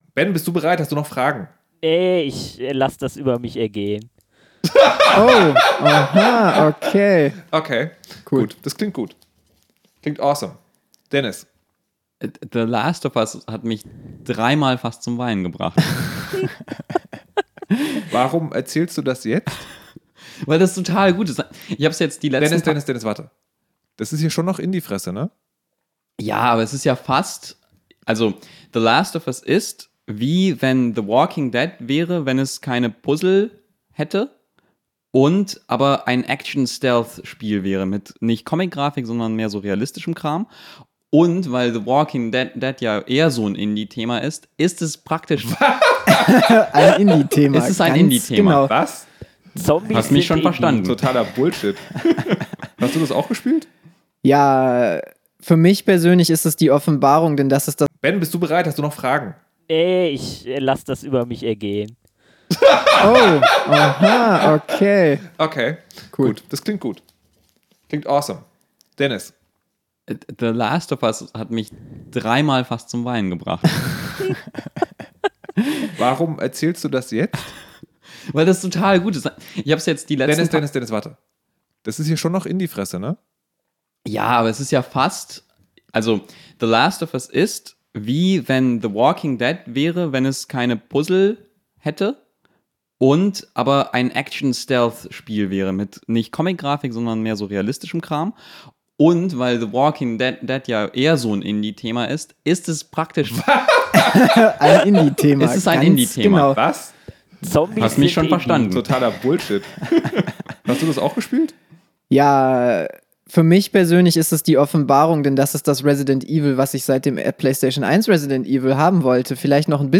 Da schafft man es einmal, einen Indie-Entwickler in die Berliner Superlevel-Studios zu schleifen und dann weigert er sich über sein eigenes Spiel zu reden.